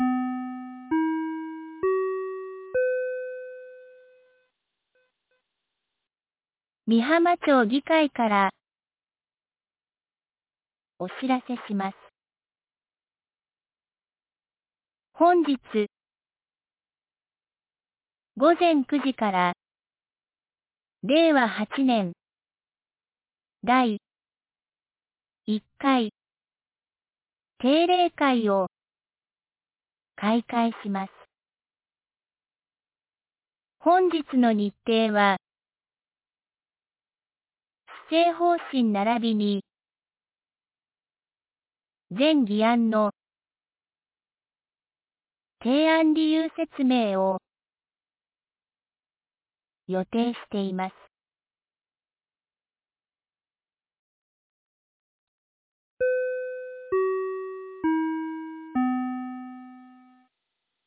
2026年03月04日 07時46分に、美浜町より全地区へ放送がありました。